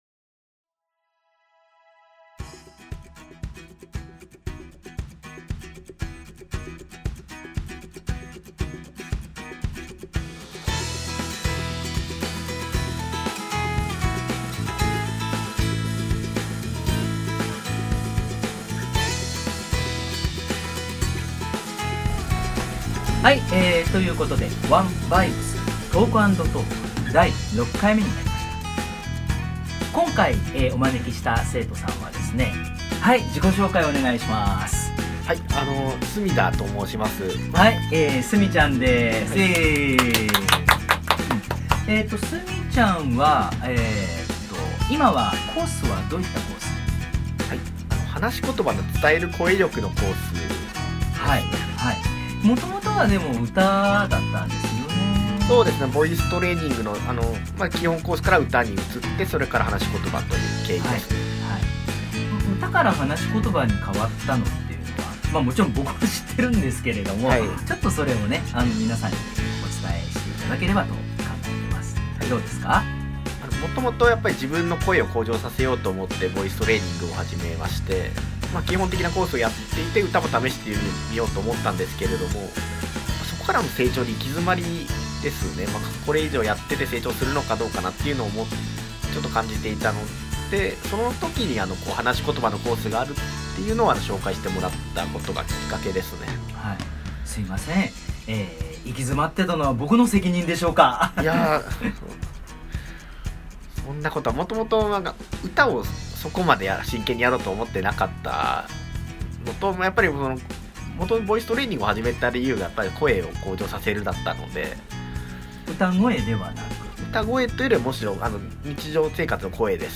今回は男性の生徒さんです。